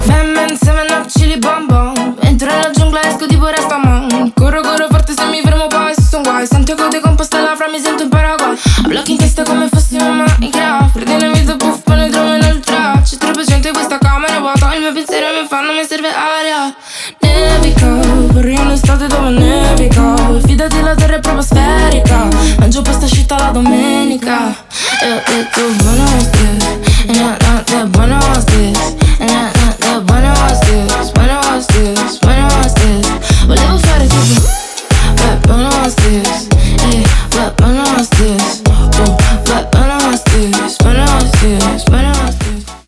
• Качество: 320, Stereo
поп
зажигательные
красивый женский голос
Зажигательный поп рингтон